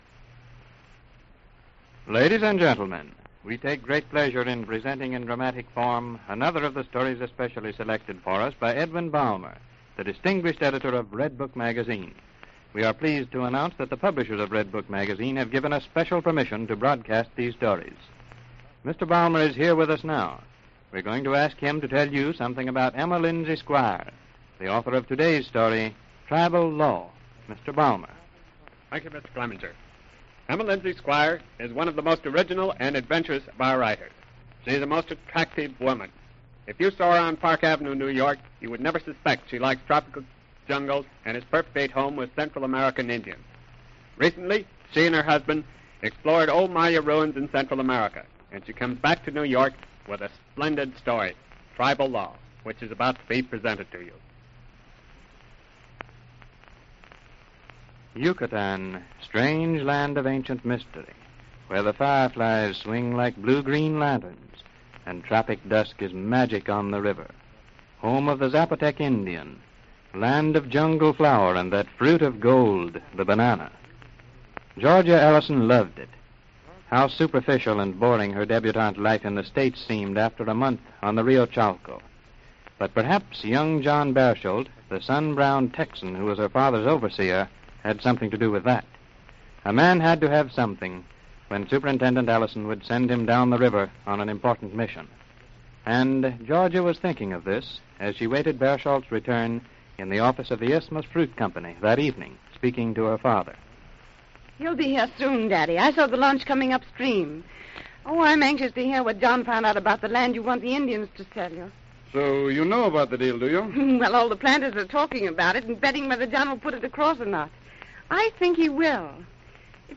The episode, like many from the series, is a testament to the storytelling prowess that radio dramas held, a medium where the imagination painted the scenes and emotions were conveyed through voice alone.